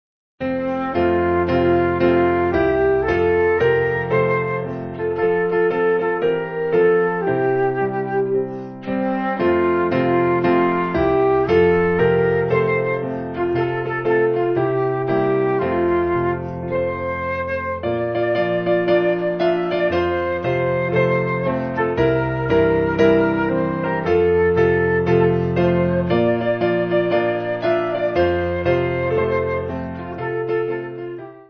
Piano and Flute